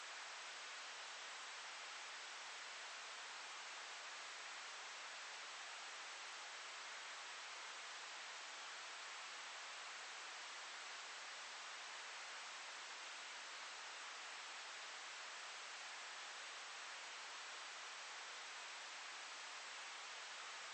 哈斯菲尔德警察的喋喋不休 " 白噪声回复
描述：回答警察的白噪声